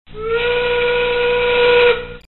Play, download and share Factory Whistle original sound button!!!!
factory-whistle.mp3